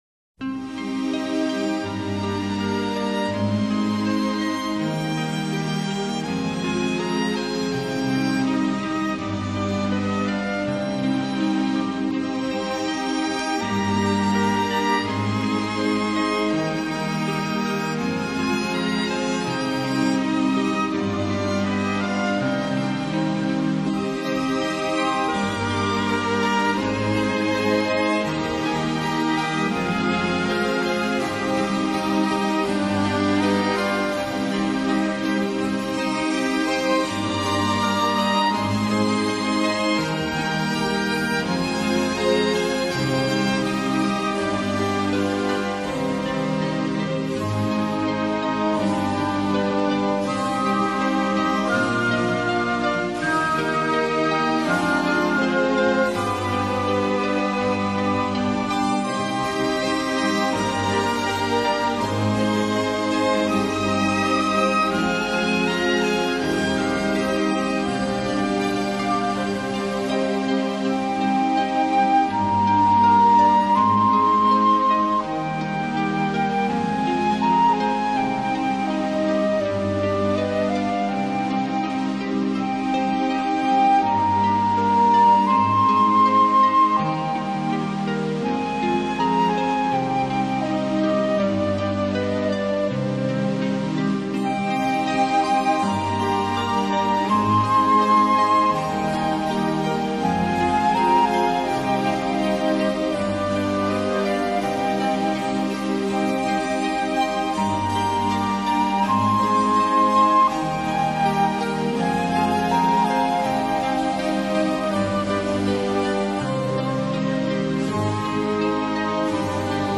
类型：NewAge
初听此专辑，没有很深的印象，平静，旋律平坦。
音乐的主题是慢慢的展开的，不紧不慢的，柔柔的，缓缓的，让我们感受到作者的情感，旋律的优美。